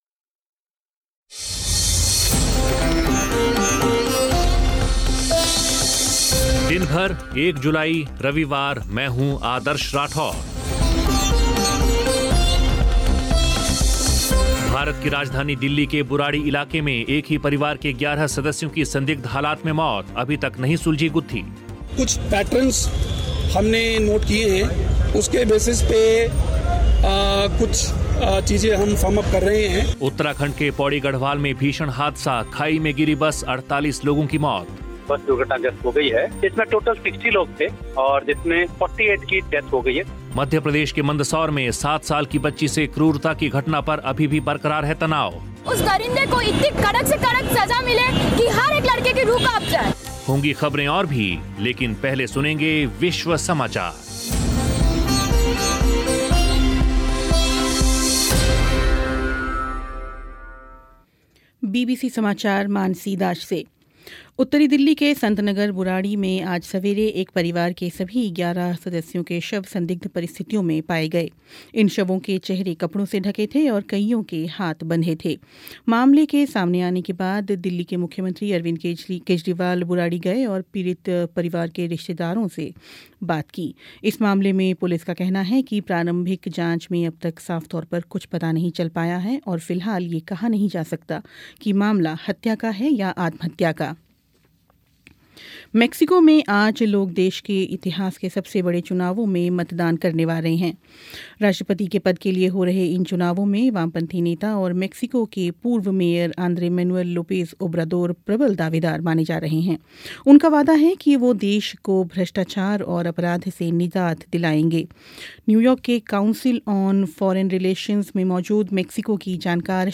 मध्य प्रदेश के मंदसौर में सात साल की बच्ची से क्रूरता की घटना पर अभी भी बरक़रार है तनाव, सुनिए ग्राउंड रिपोर्ट